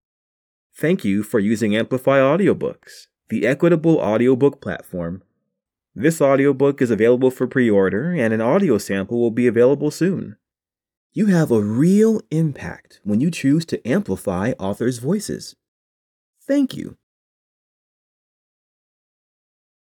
• Audiobook • 13 hrs, 35 min
• Fiction